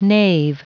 Prononciation du mot nave en anglais (fichier audio)
Prononciation du mot : nave